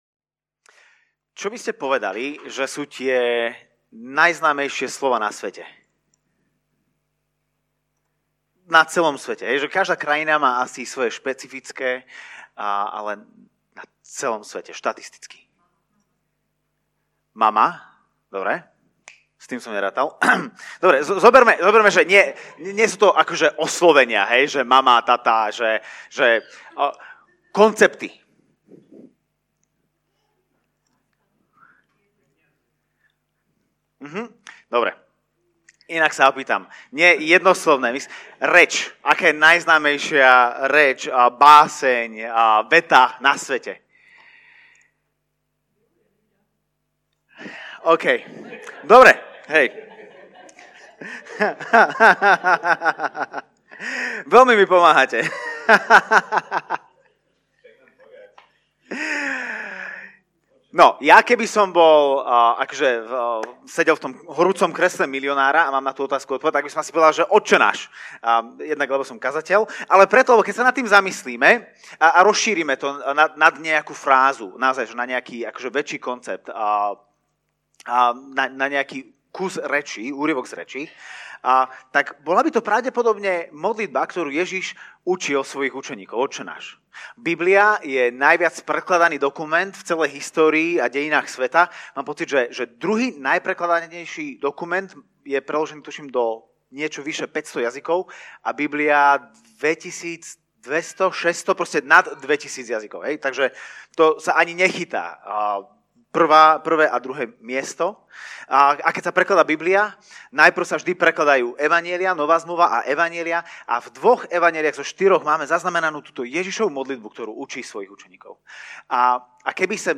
- Podcast Kázne zboru CB Trnava - Slovenské podcasty